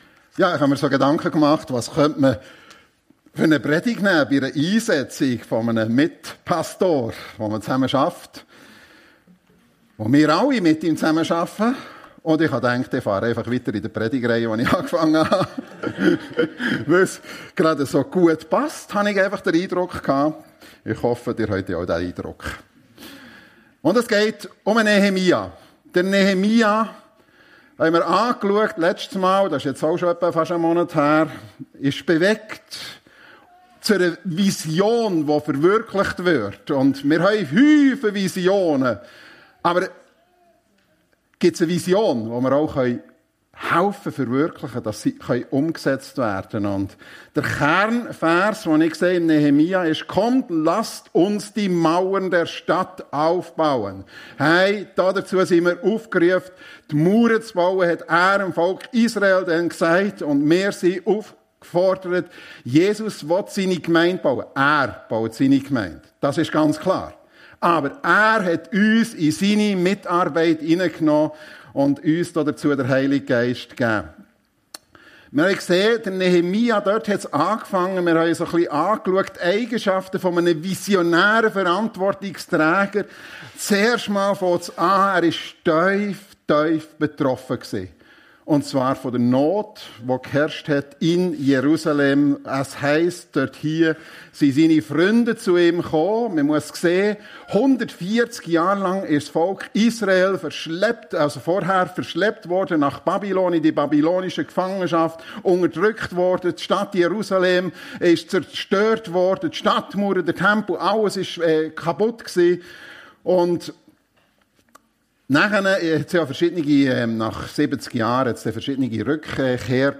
Nehemia - wie aus einer Vision Wirklichkeit wurde (Teil 2) ~ FEG Sumiswald - Predigten Podcast